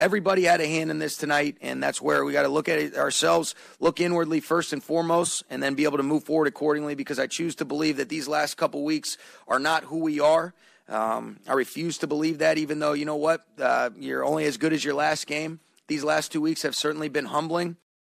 In the post-game press conference, Sean Mcvay stood clearly frustrated after a fifth consecutive defeat to the 49ers.